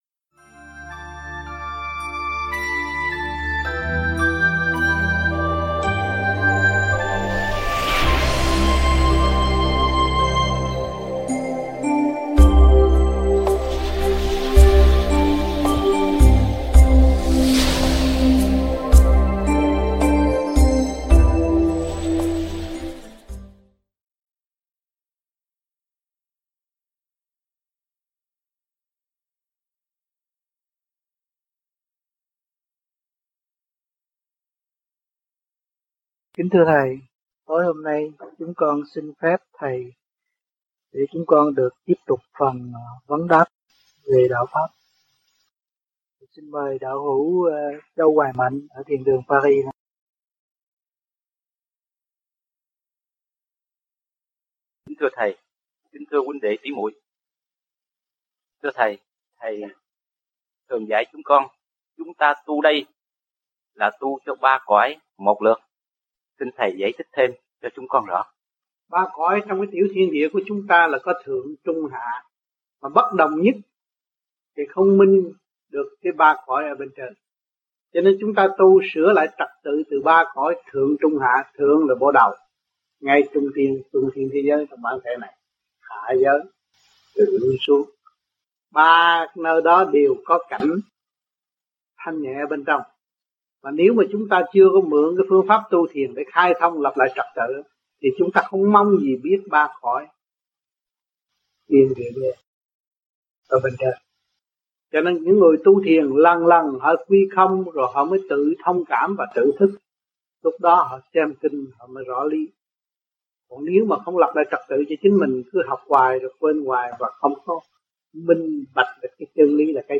THUYẾT GIẢNG